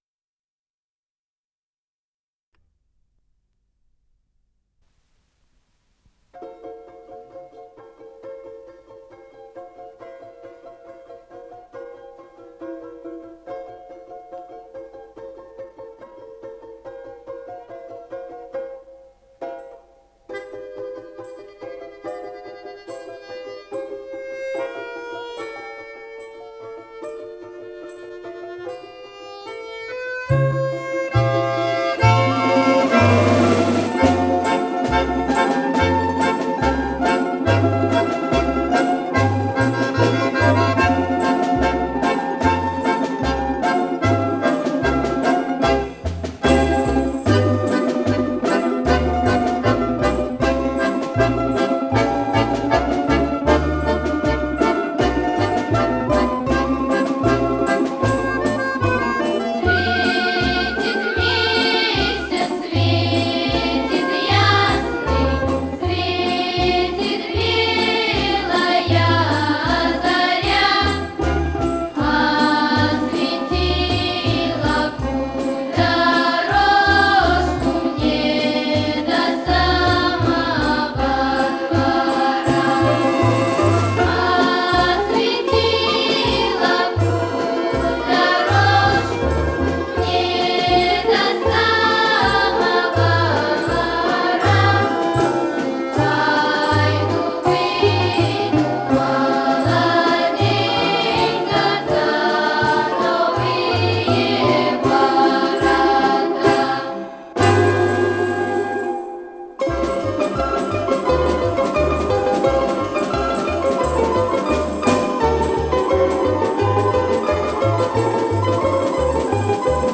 КомпозиторыРусская народная песня
ИсполнителиФольклорная группа Большого детского хора Всесоюзного радио и Центрального телевидения
АккомпаниментИнструментальный ансамбль
Скорость ленты38 см/с
ВариантДубль моно